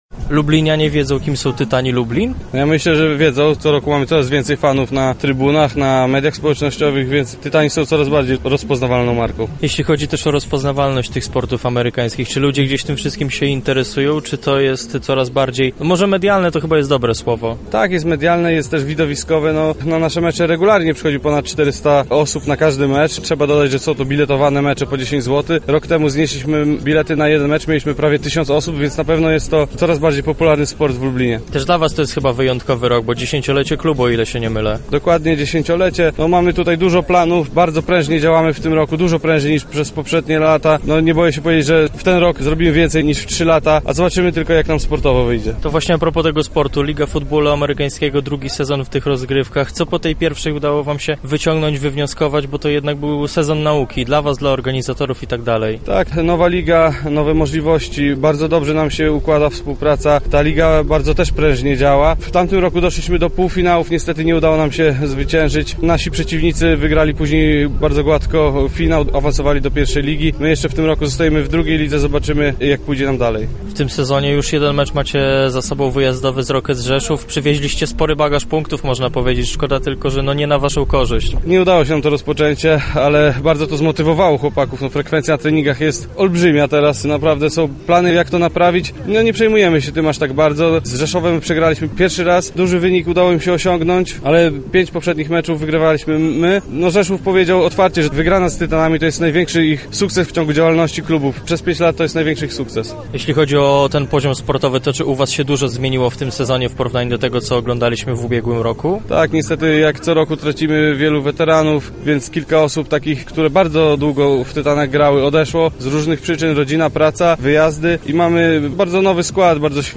Będą chcieli nie tylko dobrze spisać się w swoim drugim sezonie startów w Lidze Futbolu Amerykańskiego, ale również pokazać, że warto przychodzić na ich mecze na stadion przy al. Piłsudskiego. Więcej na ten temat usłyszycie w rozmowie